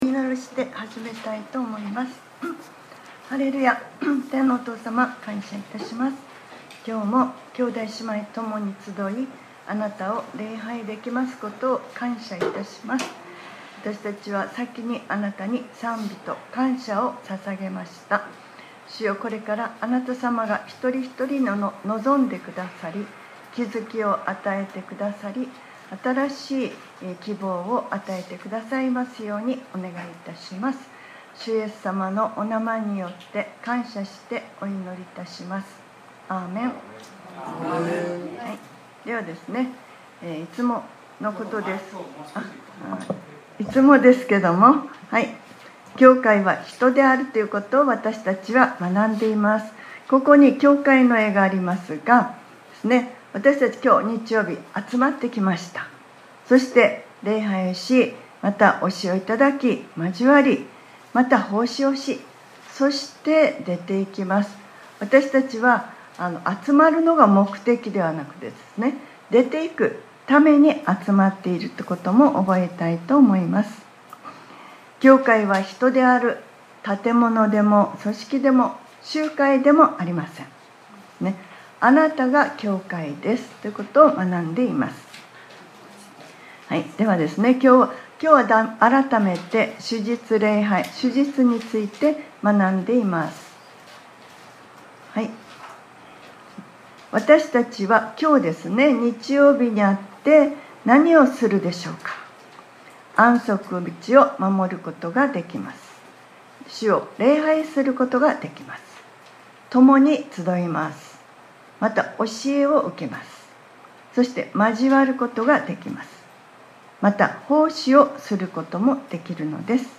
2025年07月20日（日）礼拝説教『 安息日の祝福: 奉仕 』